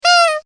Гудок на носу или бумажная дуделка